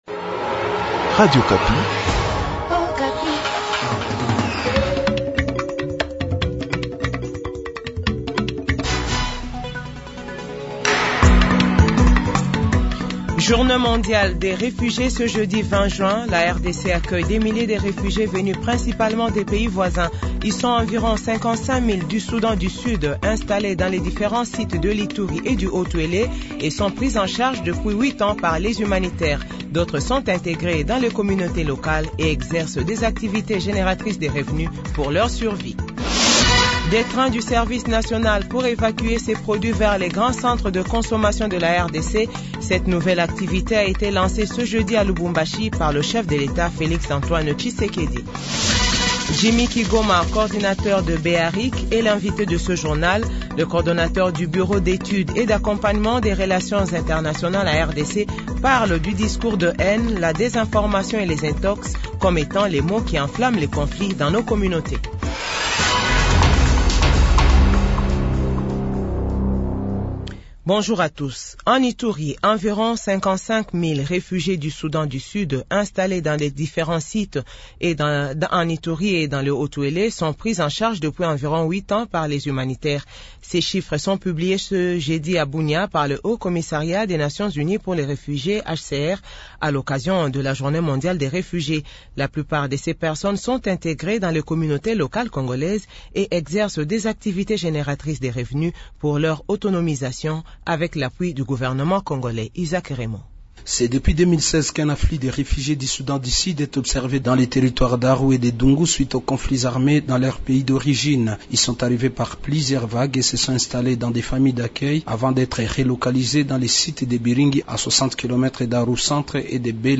JOURNAL FRANCAIS 15H00